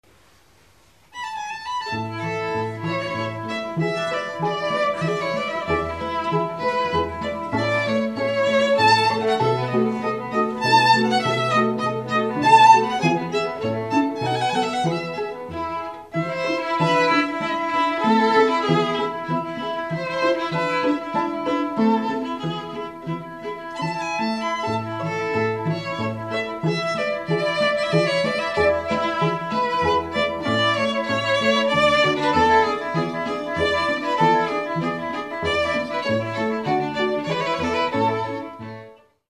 Festliche Streicherklänge zur Zeremonie
A Streichquartett (unsere Grundbesetzung: 2 Violinen, 1 Viola, 1 Violoncello)
(Besetzung A: Streichquartett)